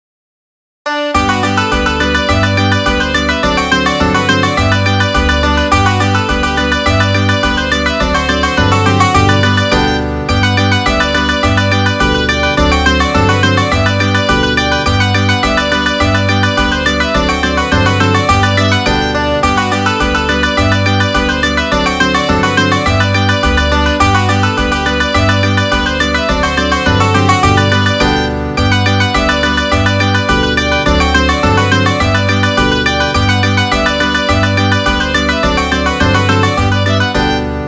Midi File, Lyrics and Information to Buffalo Gals